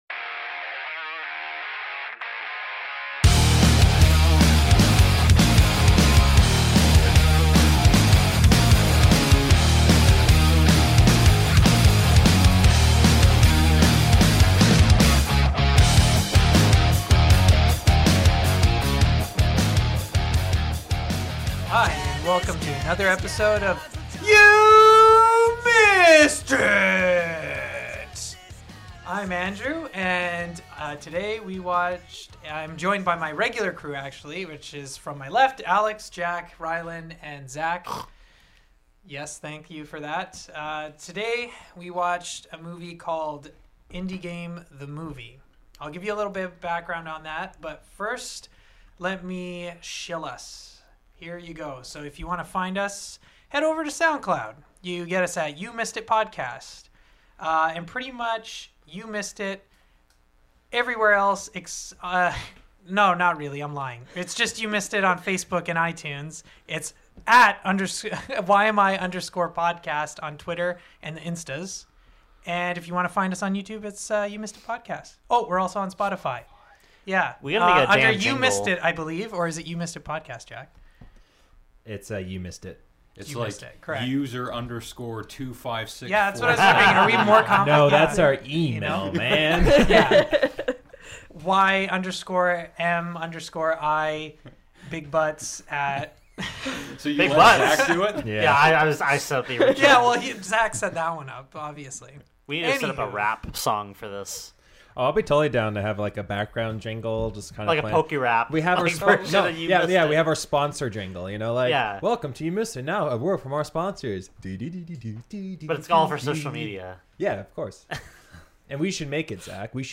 You Missed it?: A weekly podcast in which five cinephiles watch lesser-known films that were overlooked upon their initial release. Each episode, one of the hosts selects a movie to watch that they feel has not received the credit it deserves; afterwards everyone discusses and offers their opinions and their analyses to determine whether or not the movie is indeed underrated.